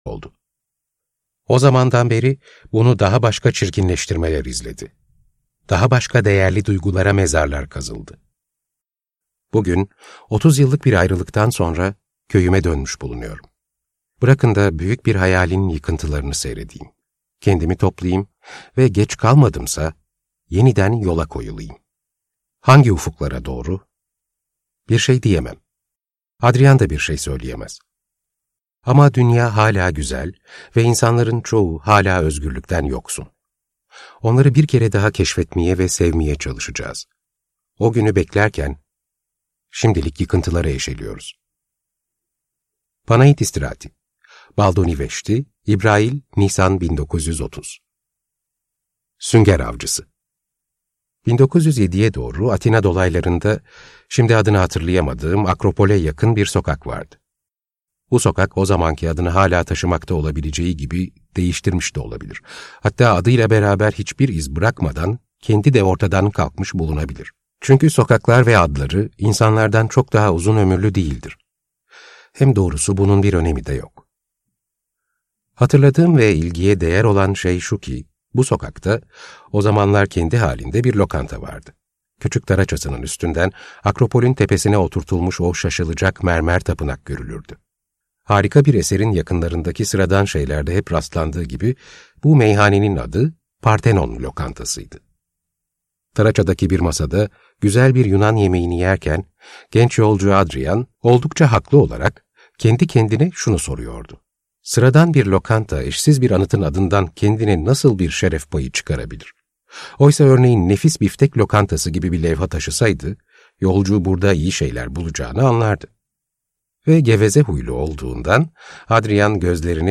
Sünger Avcısı - Seslenen Kitap